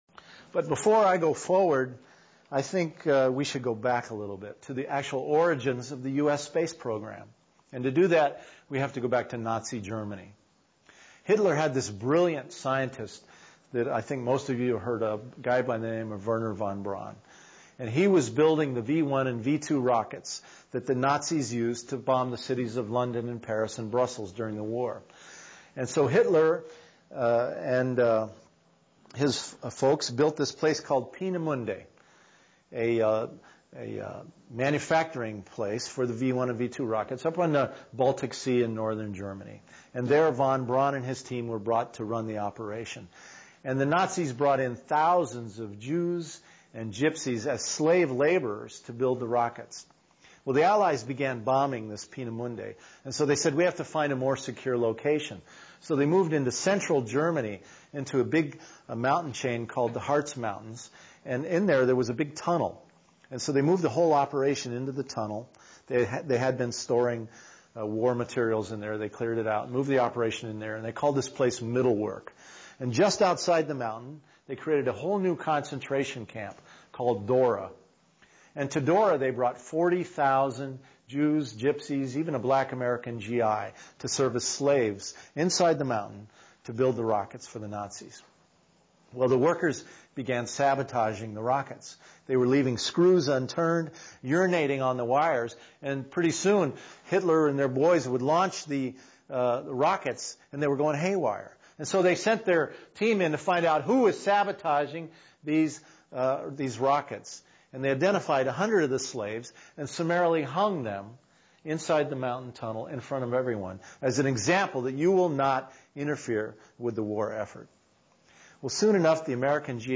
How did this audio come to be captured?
Location Recorded: Conway, NH, World Fellowship Center